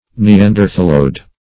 Search Result for " neanderthaloid" : The Collaborative International Dictionary of English v.0.48: Neanderthaloid \Ne*an`der*thal"oid\, a. [Neanderthal + -oid.]